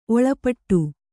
♪ oḷapaṭṭu